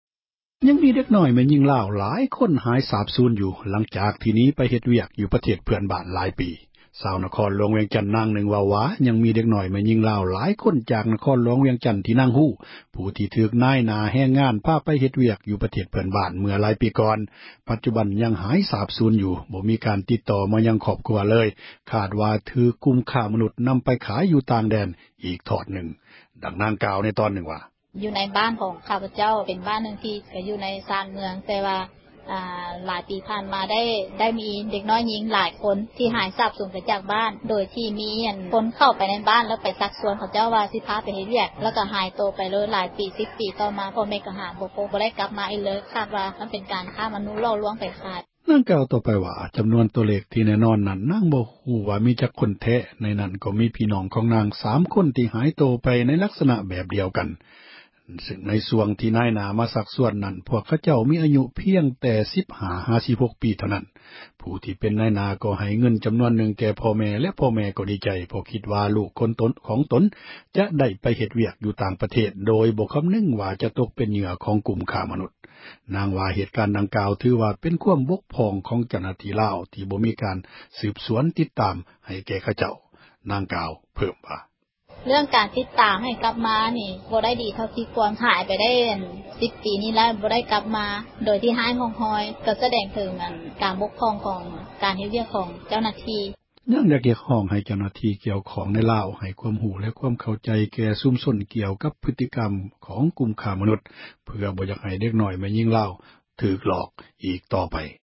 ຊາວ ນະຄອນຫລວງ ວຽງຈັນ ນາງນຶ່ງ ເວົ້າວ່າ ຍັງມີ ເດັກນ້ອຍ ແມ່ຍິງລາວ ຫລາຍຄົນ ທີ່ ນາງຮູ້ຈັກ ທີ່ ຖືກນາຍຫນ້າ ແຮງງານ ພາໄປ ເຮັດວຽກ ຢູ່ປະເທດ ເພຶ່ອນບ້ານ ໃນຫລາຍປີ ກ່ອນ ປັດຈຸບັນ ຍັງບໍ່ຮູ້ ຂ່າວຄາວ ບໍ່ມີການ ຕິດຕໍ່ ມາຫາ ຄອບຄົວ ເລີຍ ຄາດວ່າ ຖືກກຸ່ມ ຄ້າມະນຸດ ນຳໄປຂາຍ ຢູ່ຕ່າງແດນ ອີກທອດນຶ່ງ. ດັ່ງນາງ ເວົ້າວ່າ: